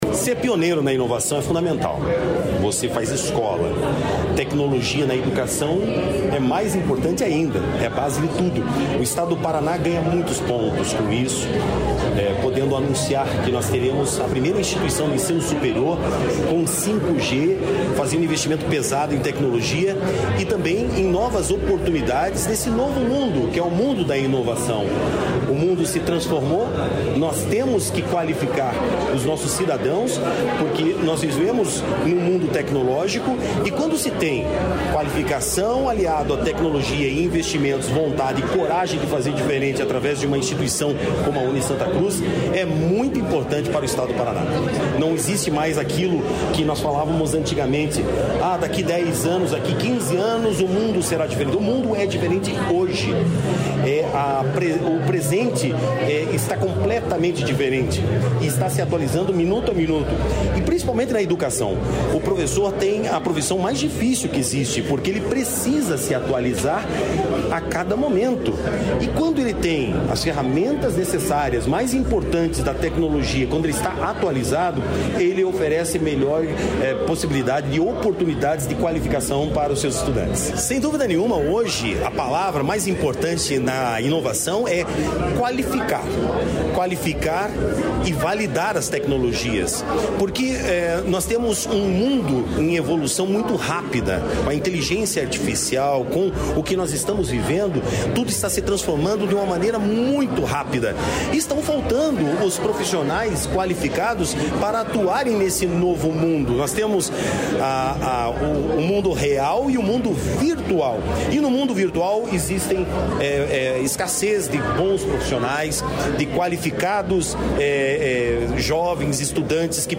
Sonora do secretário da Inovação, Marcelo Rangel, sobre a primeira rede privada 5G em instituição de ensino superior